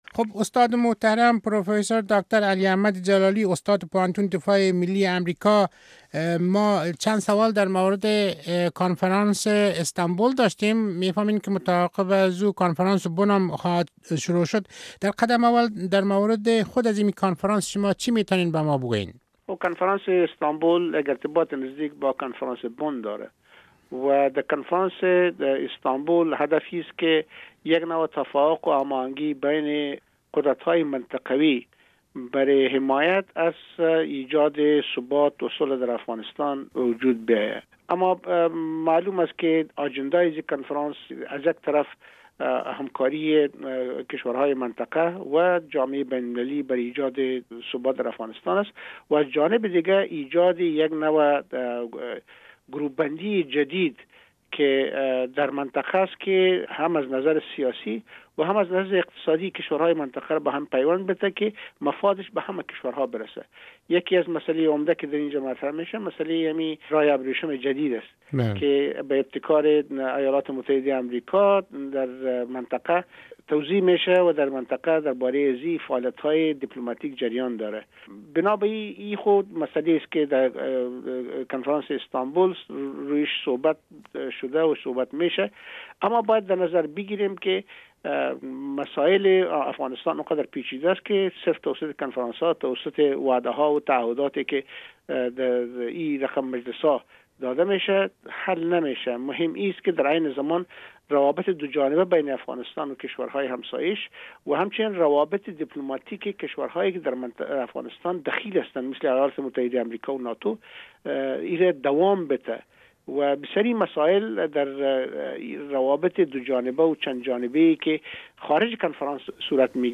مصاحبه با علی احمد جلالی در زمینهء دستاورد های کنفرانس استانبول